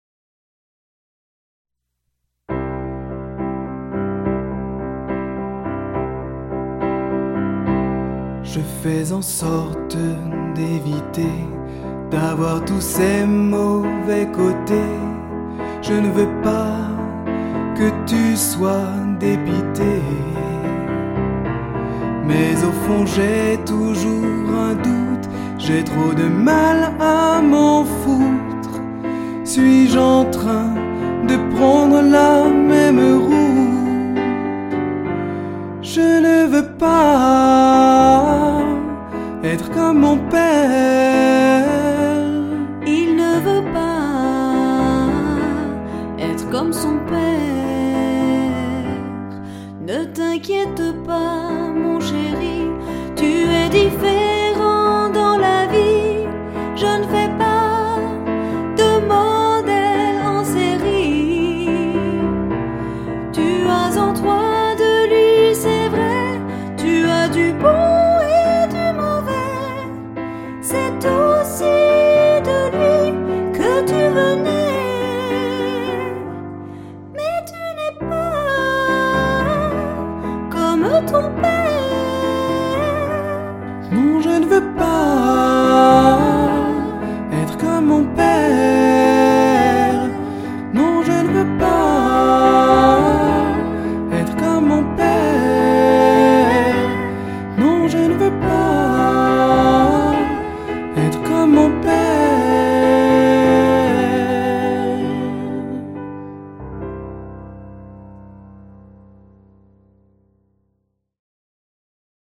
EXTRAITS MUSICAUX DU SPECTACLE